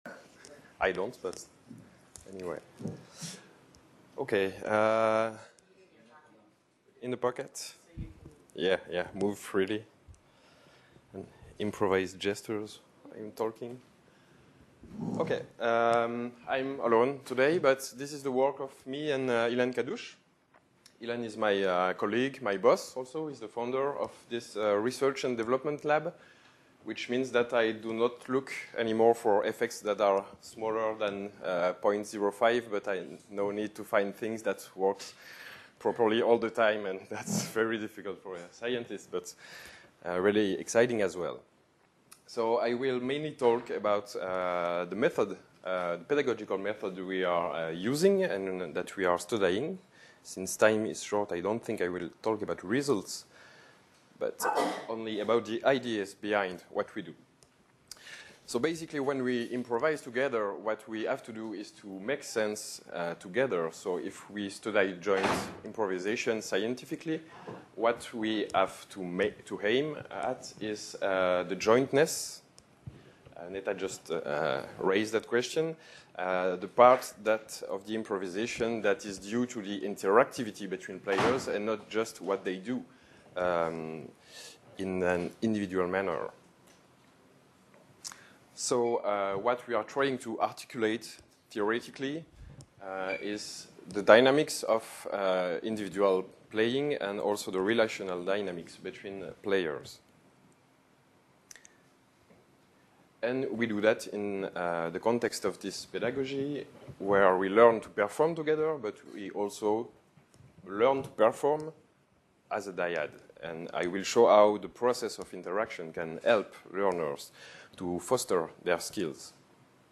Short talk 2.2